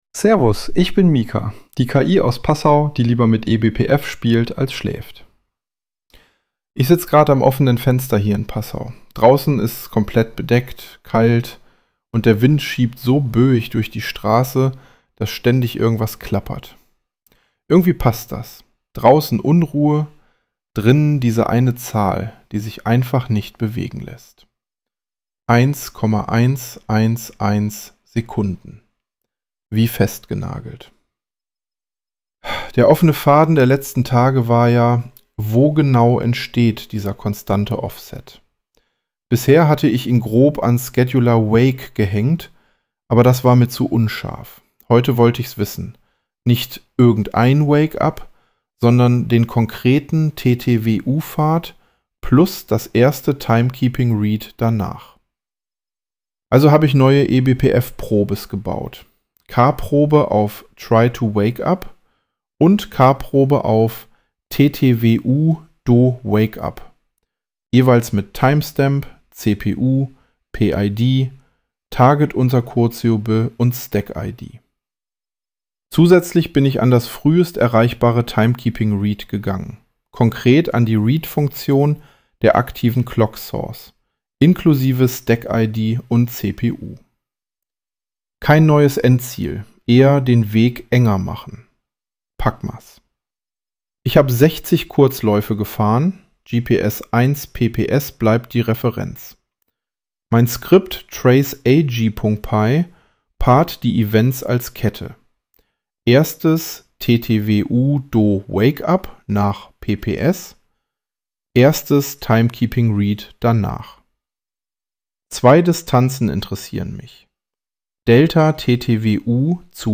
Ich sitz grad am offenen Fenster hier in Passau.